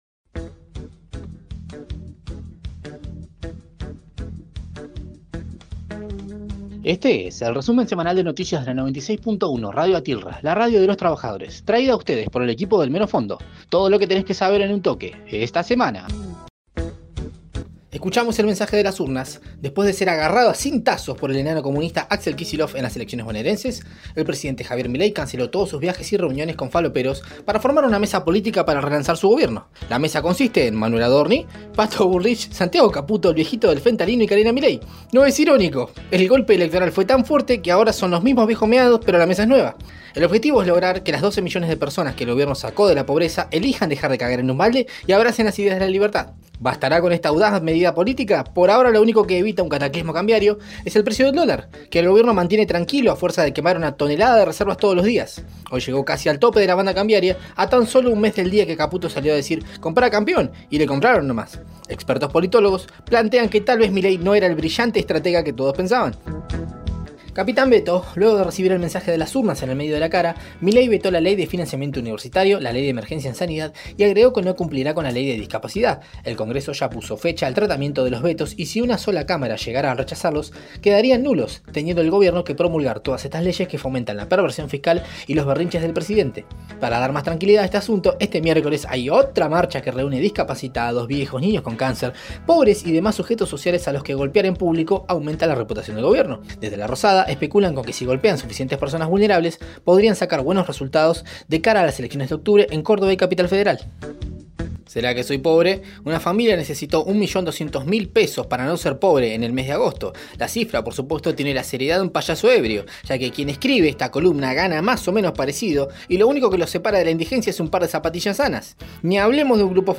Flash Informativo